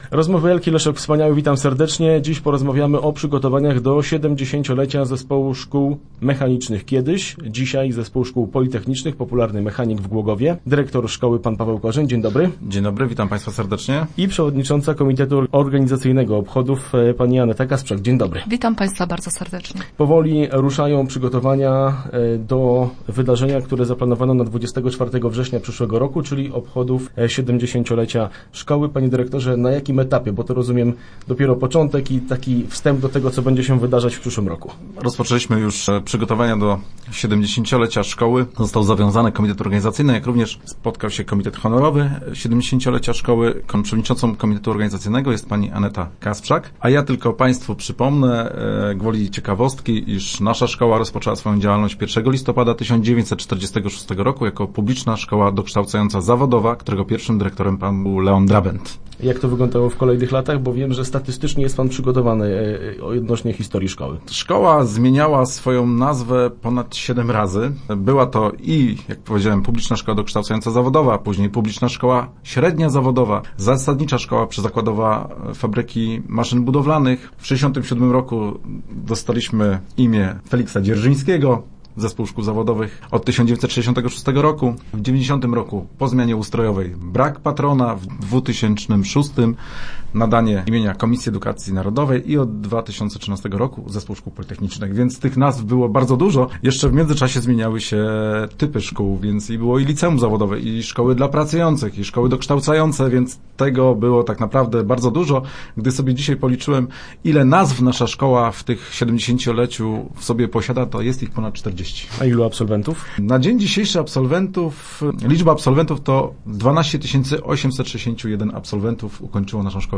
Start arrow Rozmowy Elki arrow Mechanik szykuje się do jubileuszu